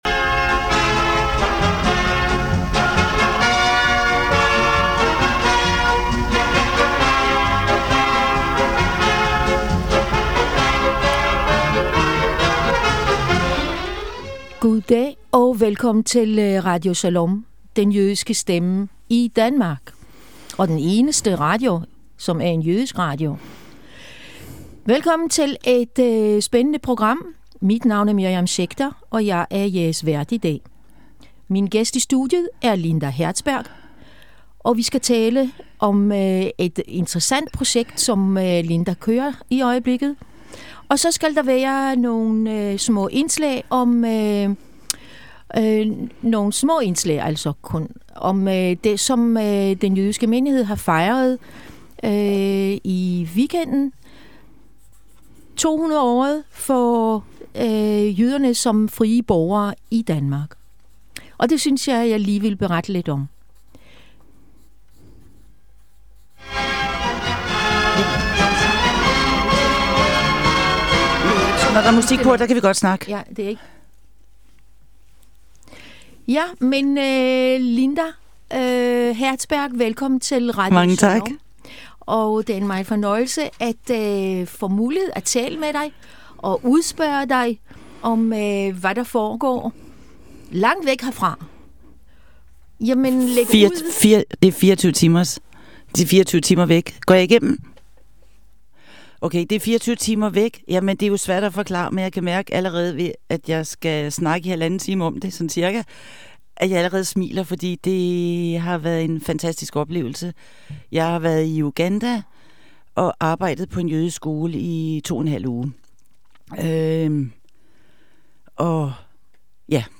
Beskrivelse: Interview